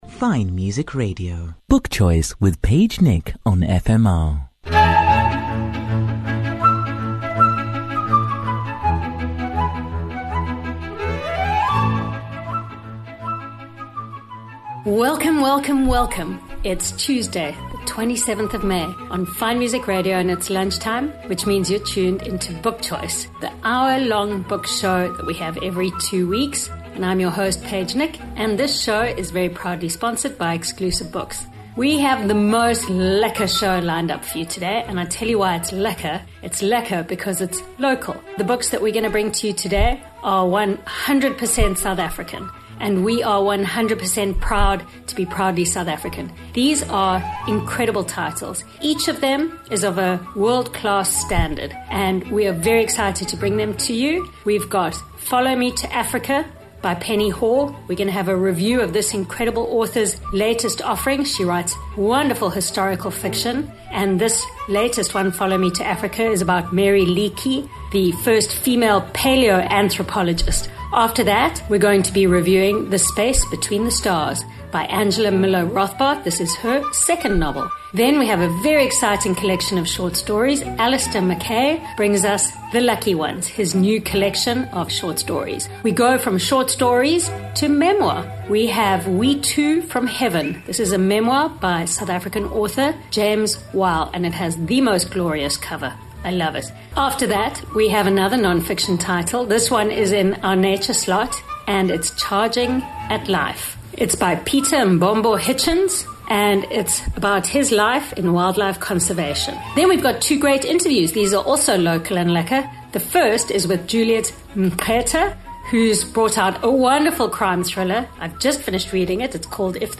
Cape Town’s top book reviewers will entertain and inform you as they cheerfully chat about the newest and nicest fiction and non-fiction on current book shelves. You love author interviews?